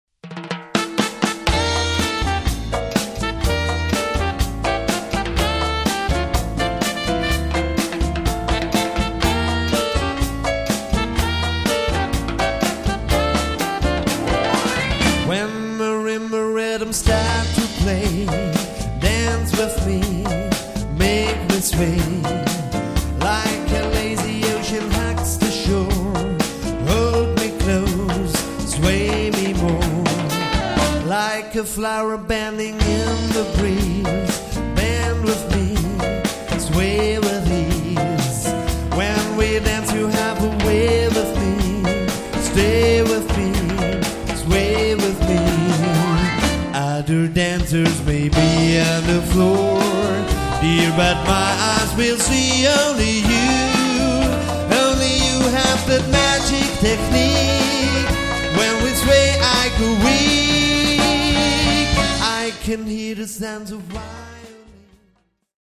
Swing  -  Latin  -  Pop  -  Soul  -  Rock  -  Tanzmusik
Sänger, Saxophon, Piano/Keyboards, Bass und Schlagzeug